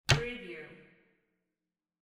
Cabinet door close sound effect .wav #6
Description: The sound of a cabinet door with magnetic catch being closed
Properties: 48.000 kHz 16-bit Stereo
Keywords: cabinet, door, close, closing, shut, shutting, metal, metallic, catch
cabinet-door-close-preview-6.mp3